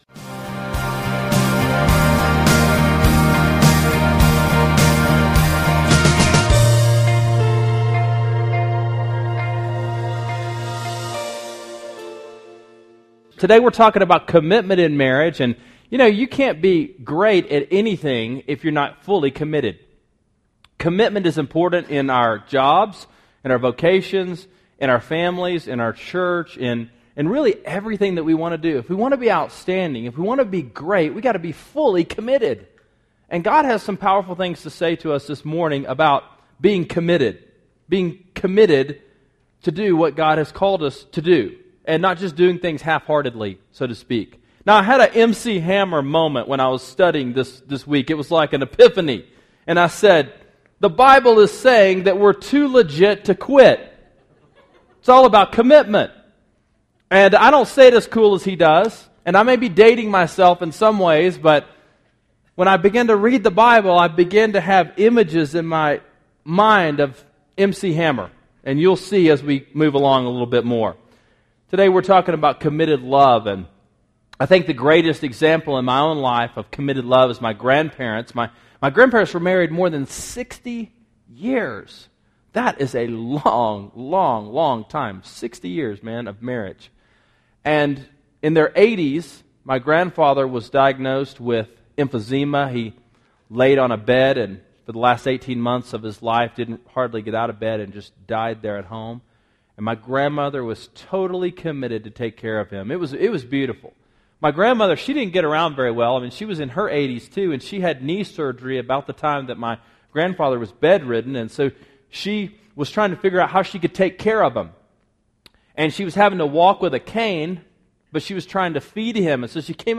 Mega Marriage: 2 Legit 2 Quit – Sermon Sidekick